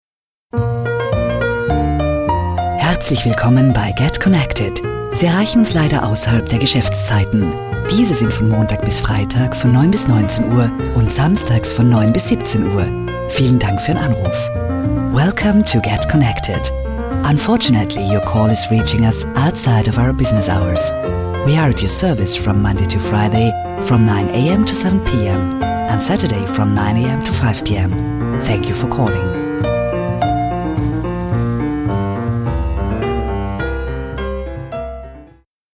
Weiblich